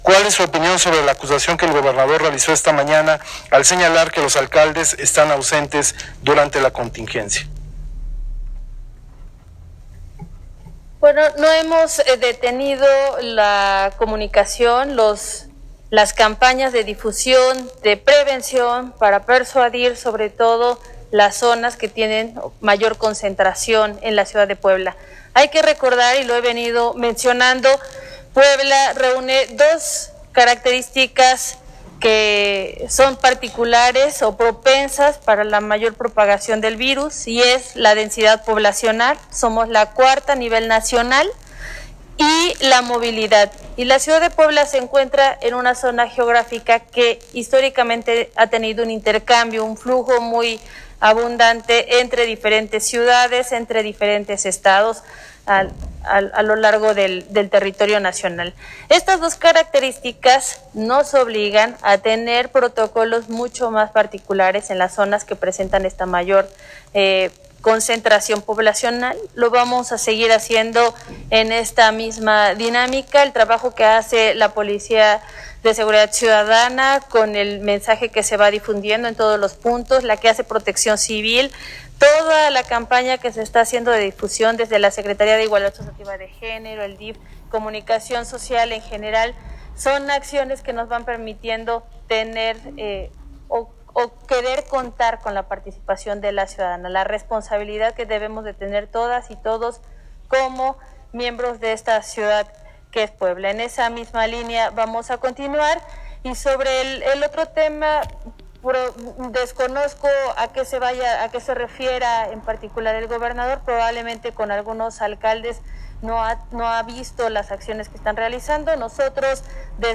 La alcaldesa Claudia Rivera Vivanco, en conferencia de prensa virtual, informó que no existen carpetas de investigación en contra de elementos de la Secretaría de Seguridad Ciudadana (SSC); además, anunció que el próximo 15 de mayo 54 nuevos cadetes se graduarán.